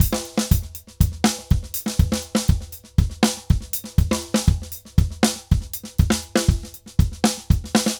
Drums_Merengue 120_2.wav